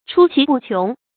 出奇不穷 chū qí bù qióng
出奇不穷发音